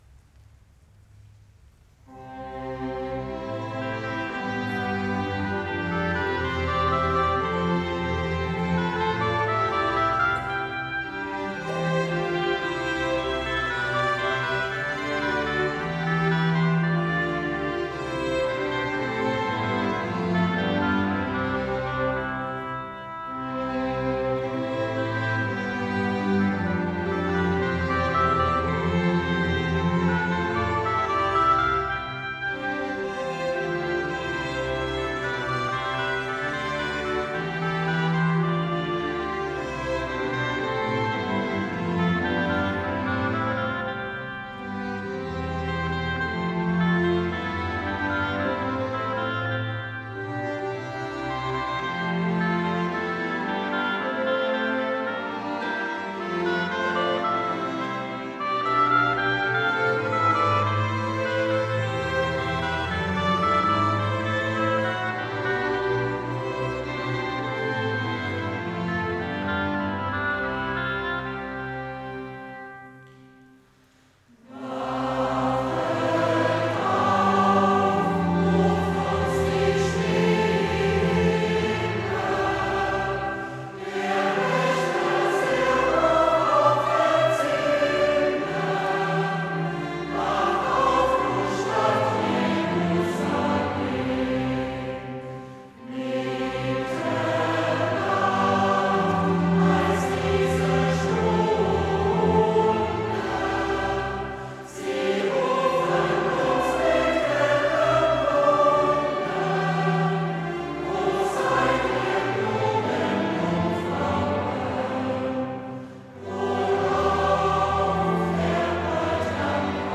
aus dem Adventskonzert 2016